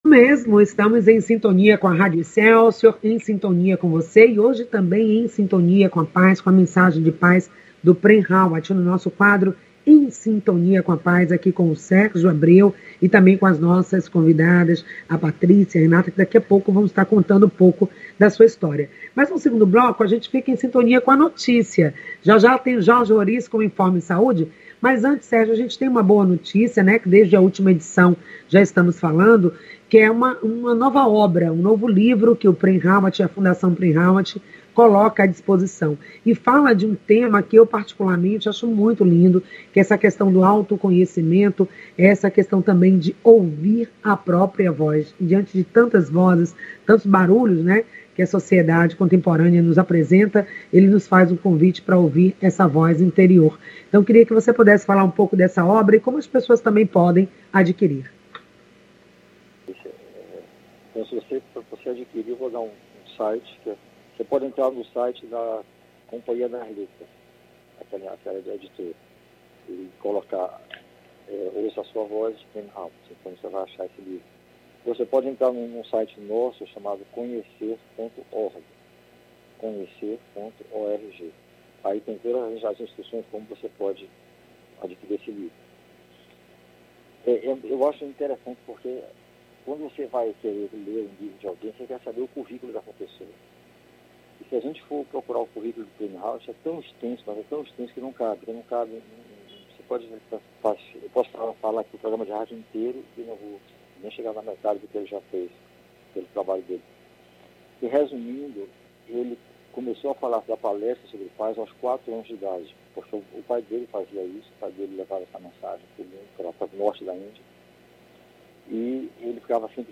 O programa acontece das 9 às 10h, pela Rádio Excelsior AM 840.